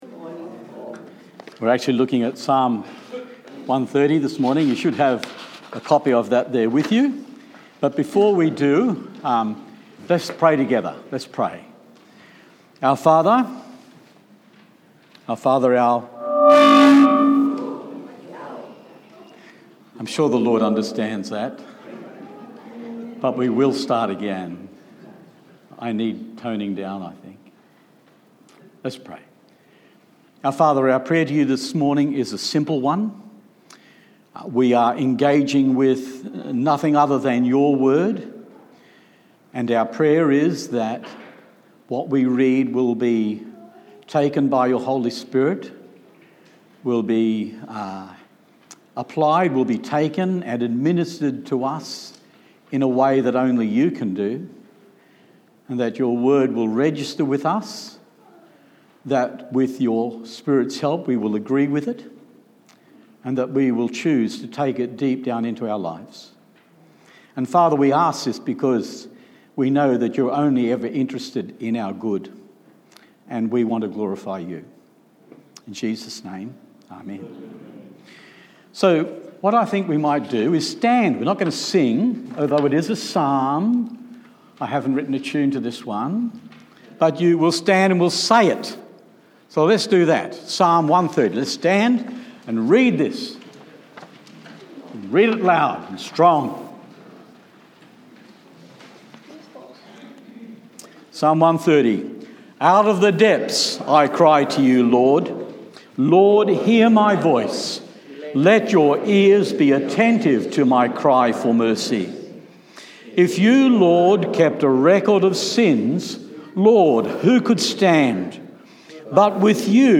Psalm 130 Service Type: Sunday morning service « Psalm 96